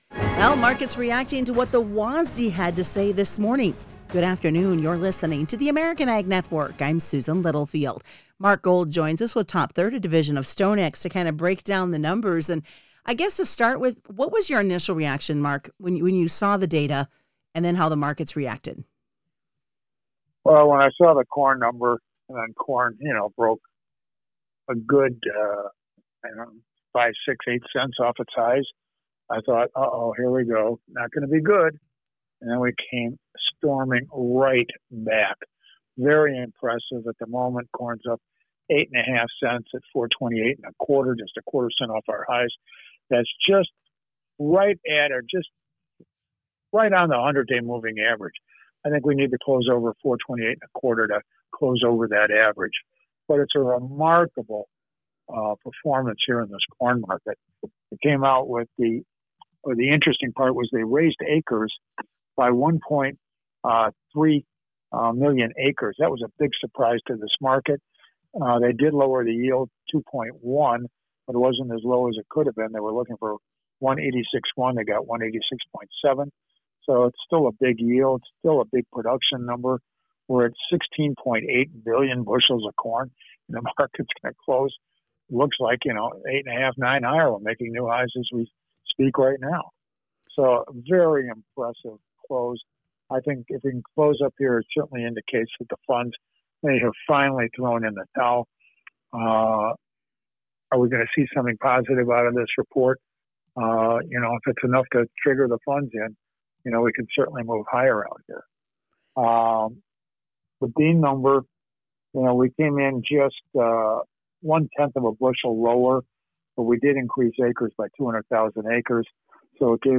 Friday Market Wrap Up